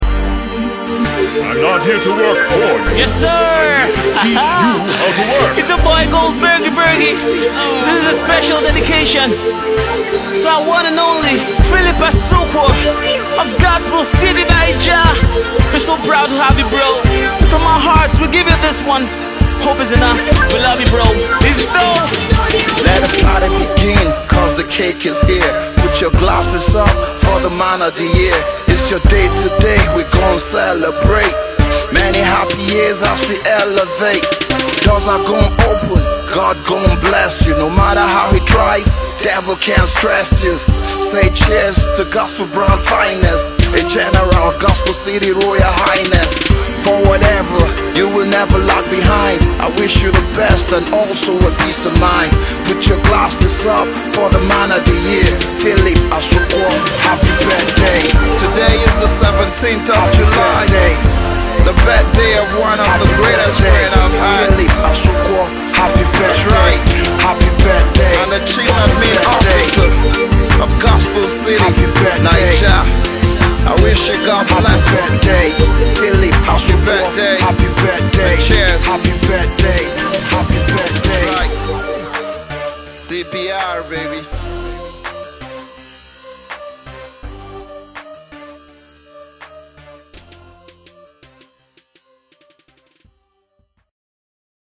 two of Port Harcourt’s prominent gospel rappers
they birthed this special birthday tribute song.